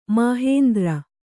♪ māhēndra